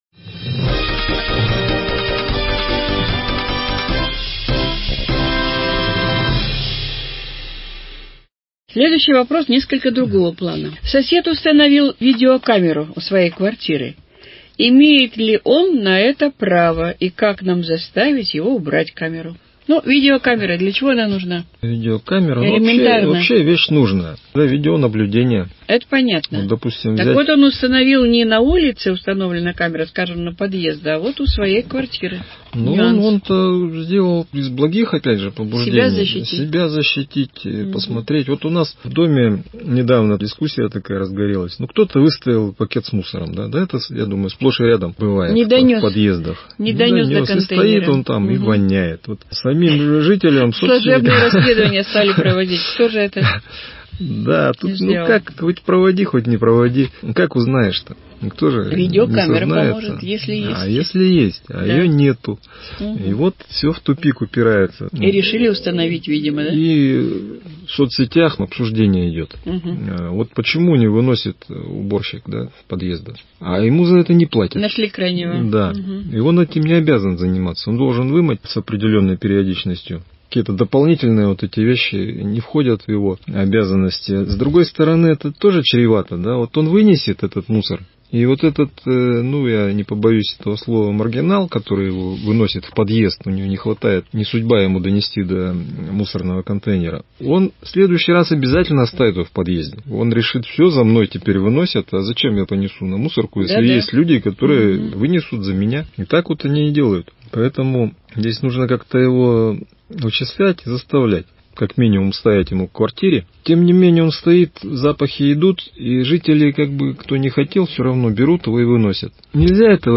Передача «Азбука ЖКХ» 2 часть. Формат передачи «Вопрос – Ответ».